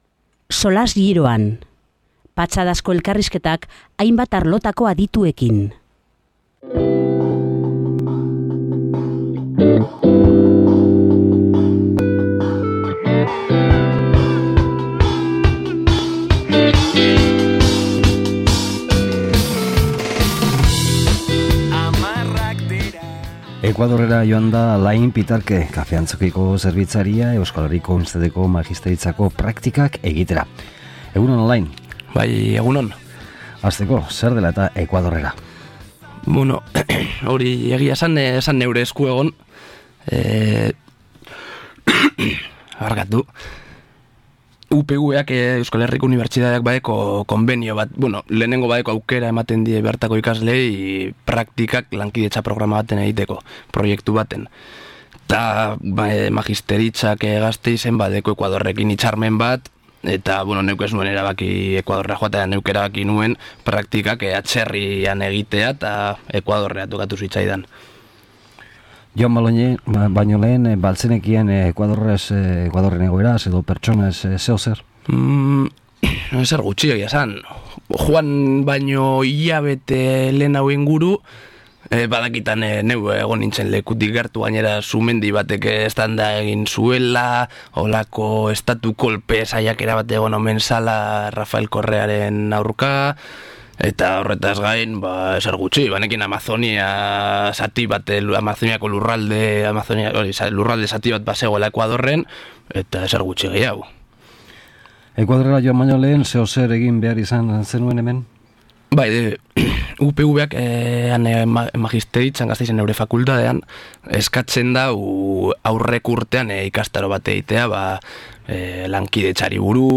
SOLASLDIA